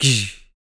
Index of /90_sSampleCDs/ILIO - Vocal Planet VOL-3 - Jazz & FX/Partition H/1 MALE PERC
PERC 018.wav